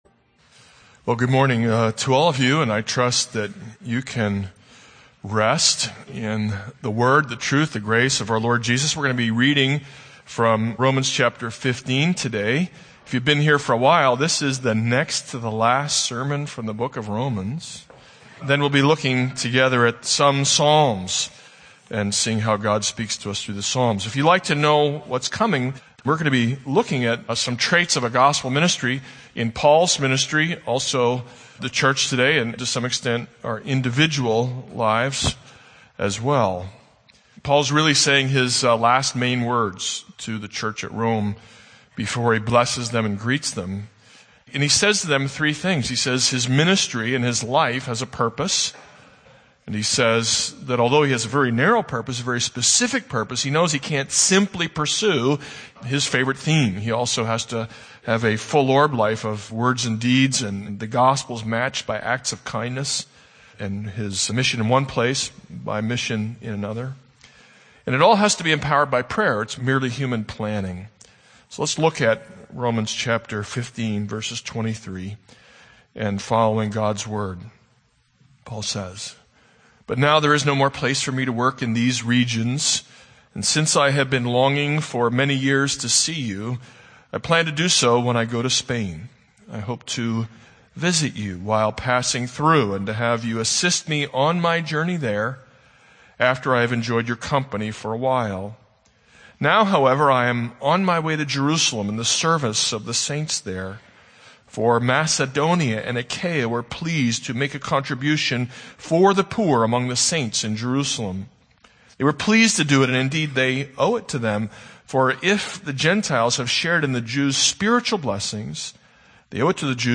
This is a sermon on Romans 15:23-33.